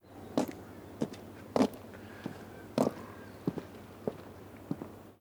Chico camina sobre asfalto con unas botas grandes